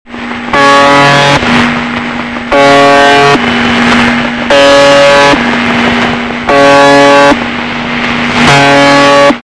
Any short wave radio enthusiast will eventually bump into stations which continuously transmit spooky stuff like this.